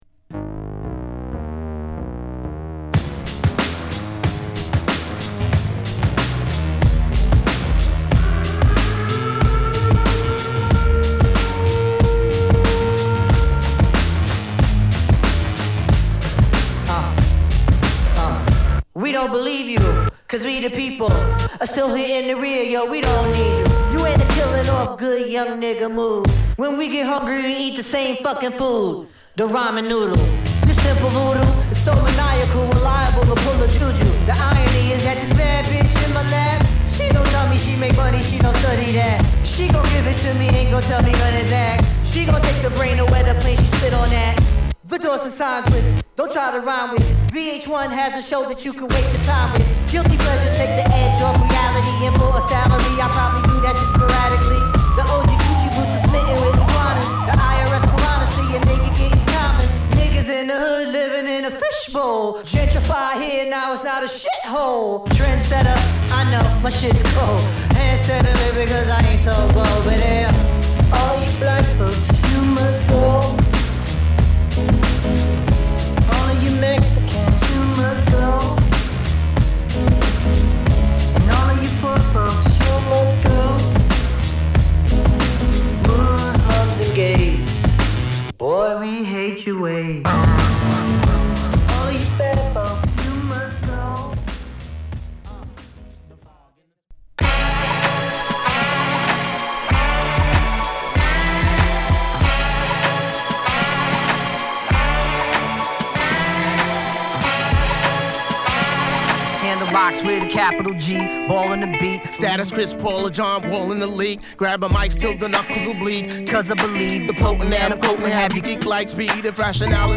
(7") Hip Hop / R&B